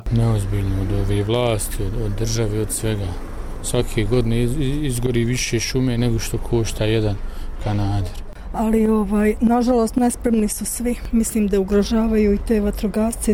Sličnih stavova su i građani Sarajeva: